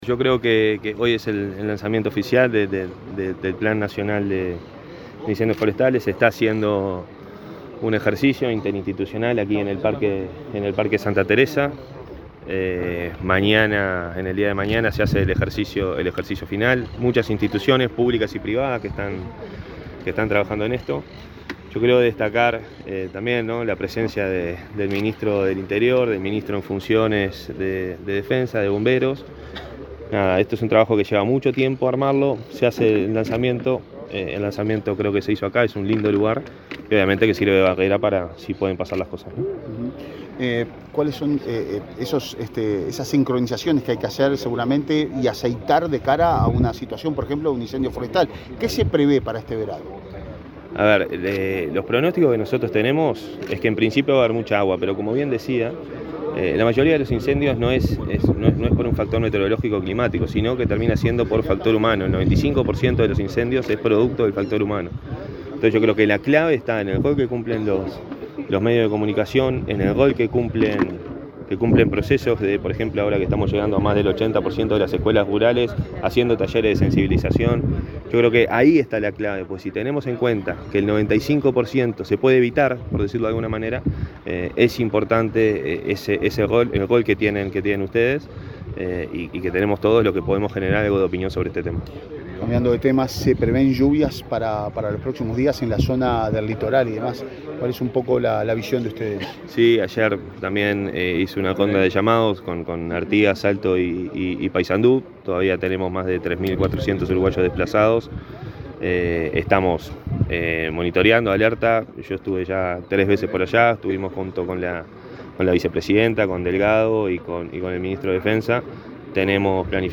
Declaraciones del director nacional de Emergencias, Santiago Caramés
El subdirector nacional de Bomberos, Richard Barboza; el director nacional de Emergencias, Santiago Caramés; y el ministro del Interior, Nicolás Martinelli, participaron este miércoles 29 en Rocha, del lanzamiento del Plan Nacional de Protección Integral frente a Incendios Forestales 2023-2024. Luego, Caramés dialogó con la prensa.